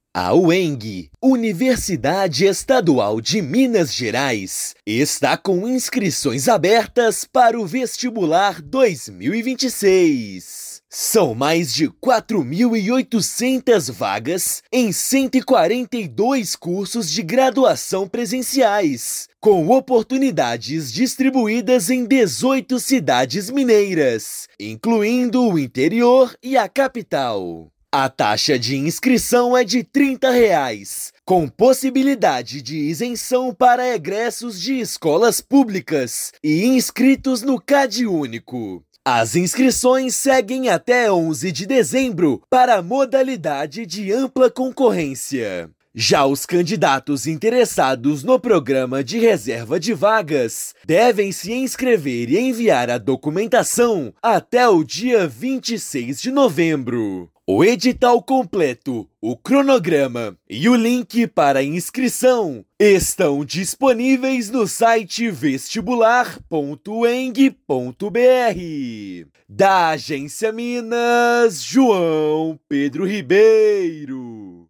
Universidade oferece 4.811 oportunidades em 142 cursos presenciais distribuídos em 18 cidades mineiras; inscrições começam em 12/11. Ouça matéria de rádio.